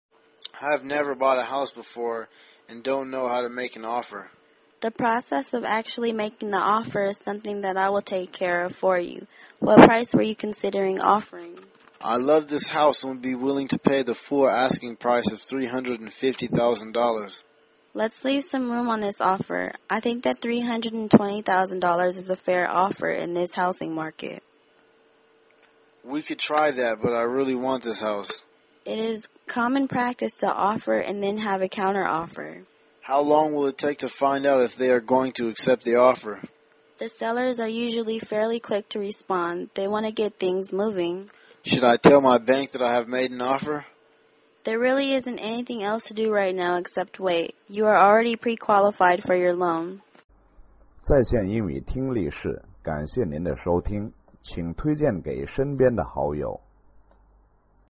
英语情景对话-Making an Offer through a Realtor(2) 听力文件下载—在线英语听力室